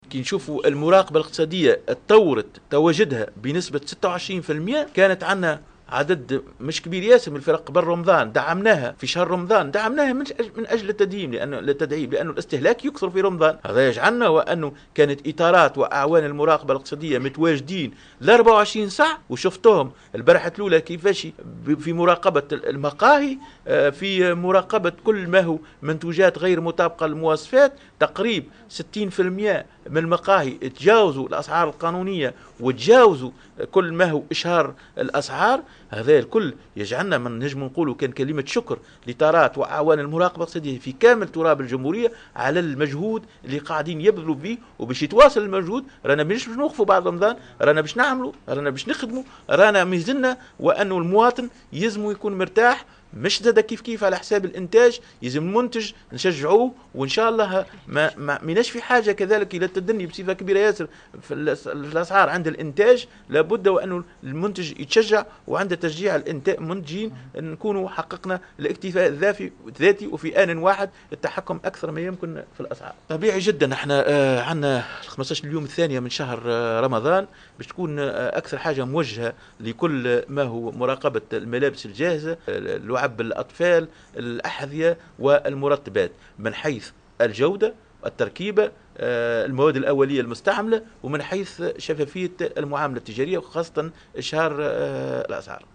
خلال اشرافه على ندوة صحفية حول أهم النتائج المسجلة خلال الأسبوعين الأولين من شهر رمضان